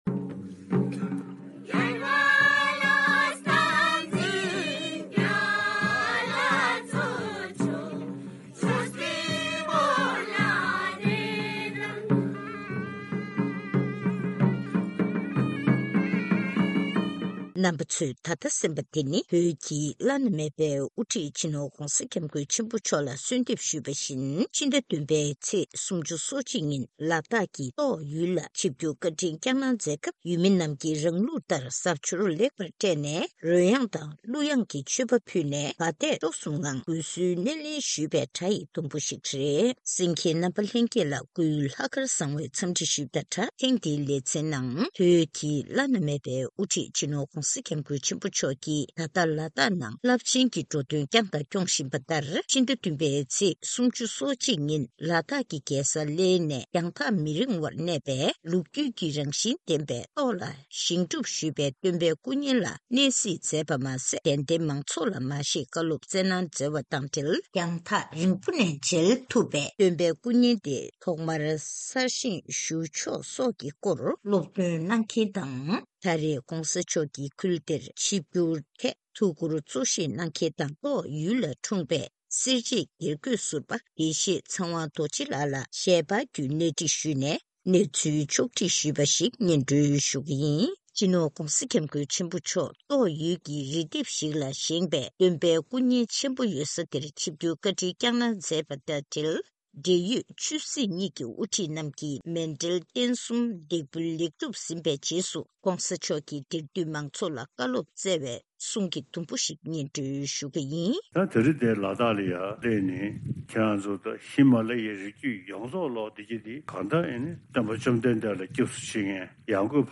གནས་འདྲི་ཞུས་ནས་གནས་ཚུལ་ཕྱོགས་སྒྲིག་ཞུས་པ་ཞིག་གསན་རོགས་གནང་།